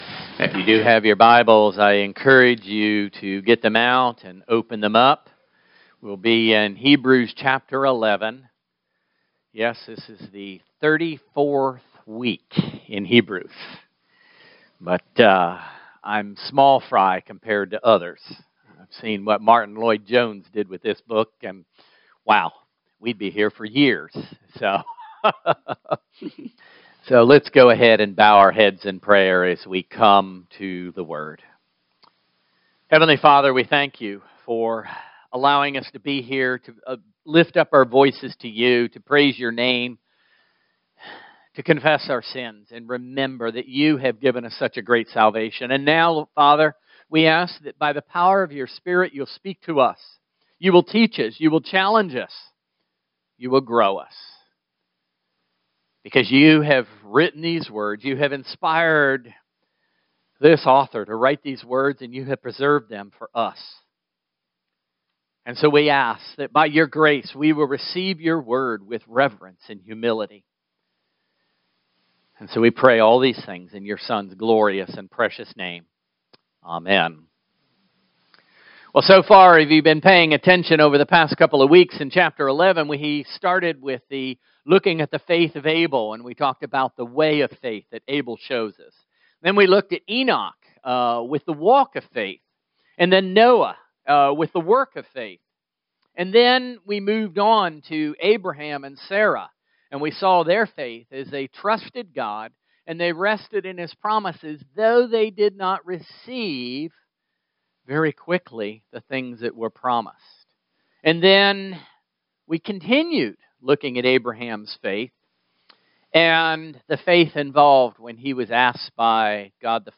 1 Samuel 14:1-23 Service Type: Sunday Morning Worship